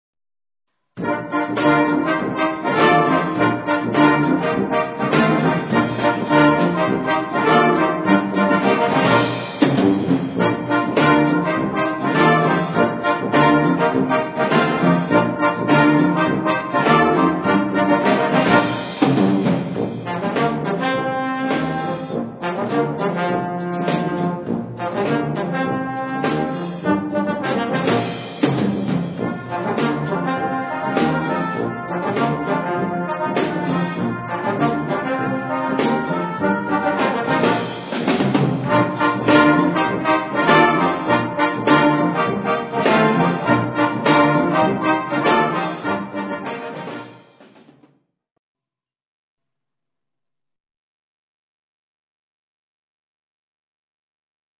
een sfeervolle en opzwepende calypso die danskriebels geeft